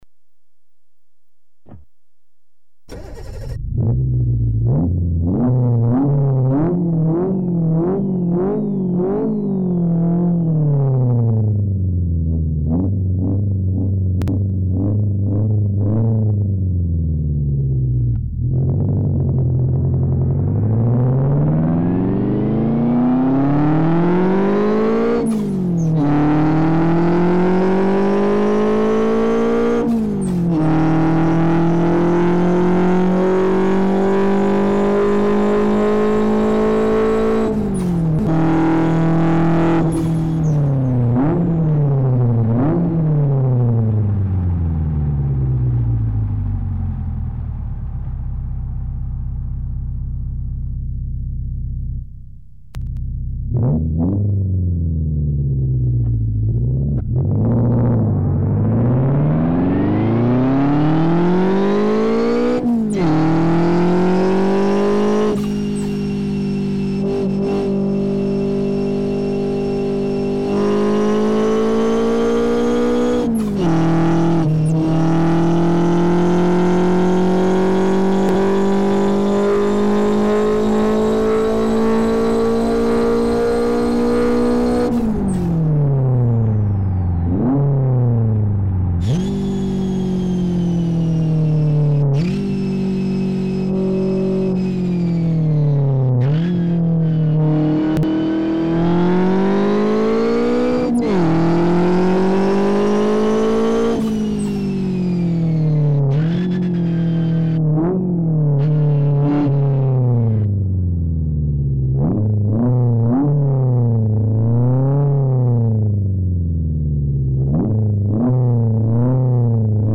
Звук_двигателя_и_свист_турбины__до_мурашек
Zvuk_dvigatelya_i_svist_turbiny__do_murashek.mp3